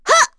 Erze-Vox_Attack1.wav